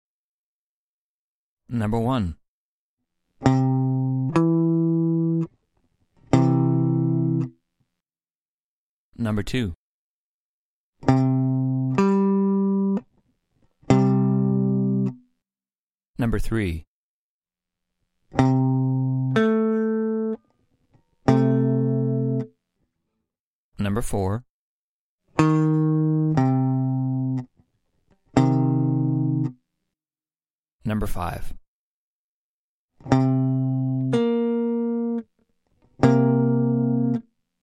• instrumentation : guitare